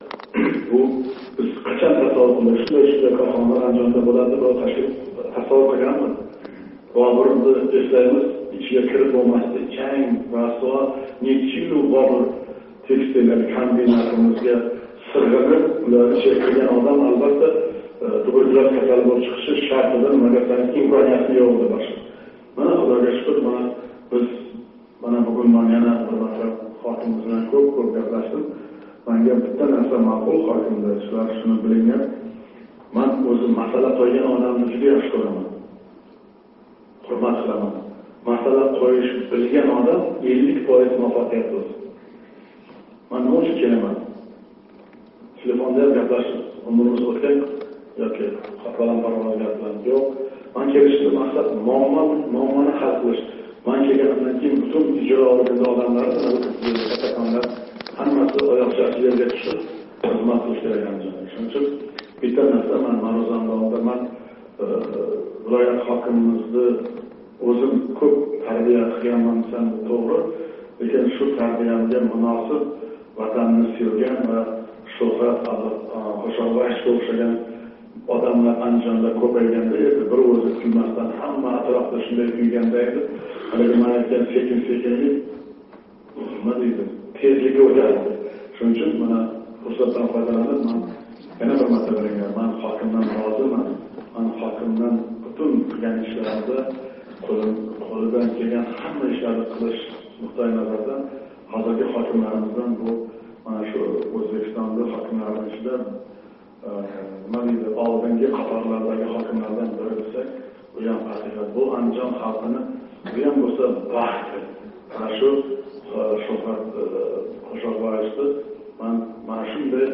19 май куни Андижон вилоятига сафарини бошлаган президент Мирзиёев Улуғнор тумани ҳокимлигида вилоятдаги ҳокимлик органлари, давлат ташкилотлари раҳбарлари билан йиғилиш ўтказди. Йиғилишда президент вилоят ҳокимига мутлақ ишонч билдирди.